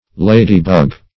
Ladybug \La"dy*bug`\, n. (Zool.)